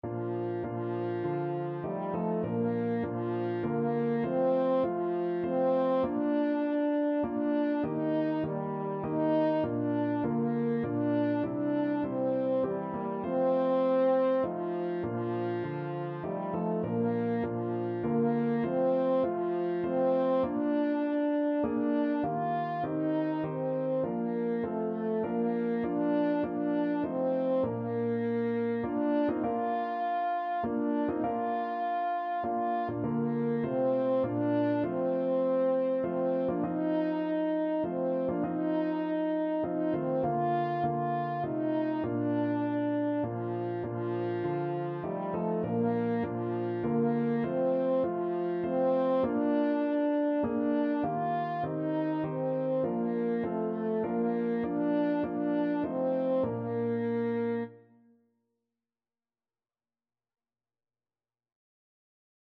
Free Sheet music for French Horn
French Horn
F4-F5
3/4 (View more 3/4 Music)
Traditional (View more Traditional French Horn Music)